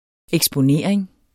Udtale [ εgsboˈneˀʁeŋ ]